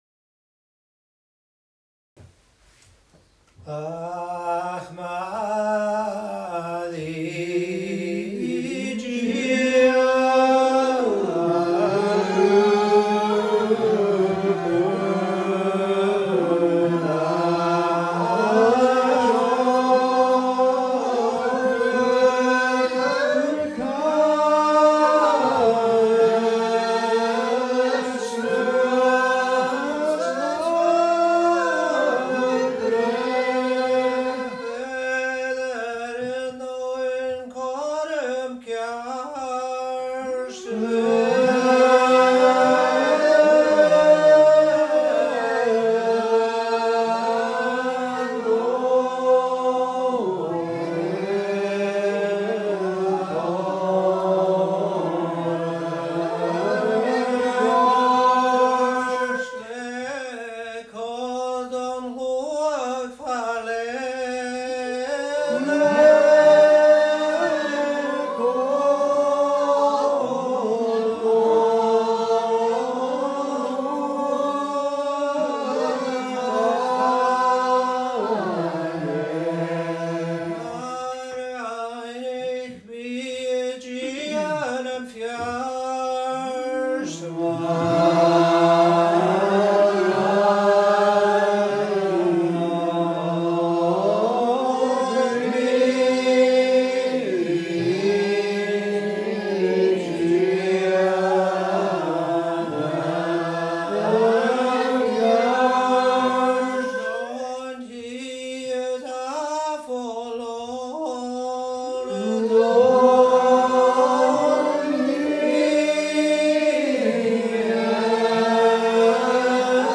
Gaelic Psalmody - class 5
A’ Seinn nan Sailm Gaelic Psalmody